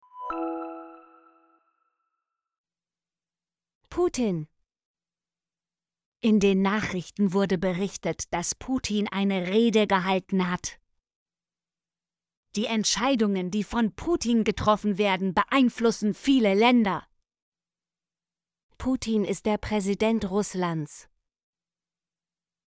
putin - Example Sentences & Pronunciation, German Frequency List